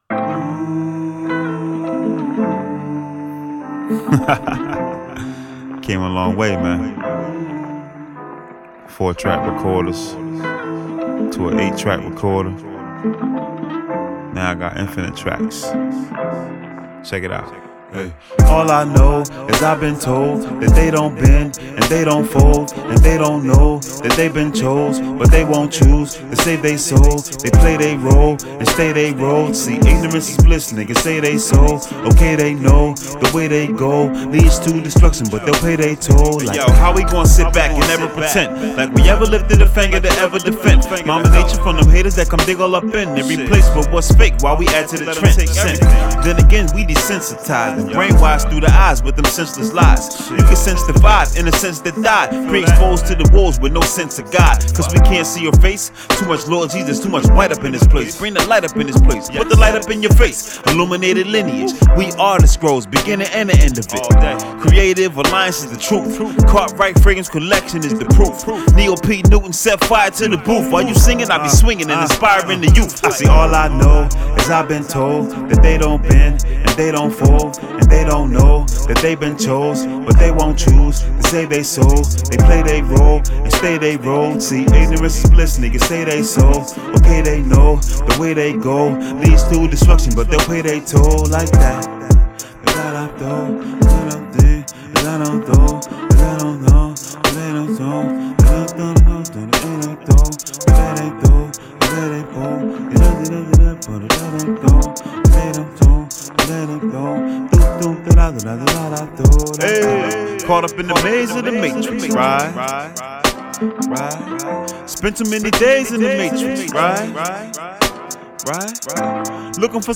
Hiphop
Description : Dope vibes to ride to..